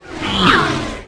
flyby_b.wav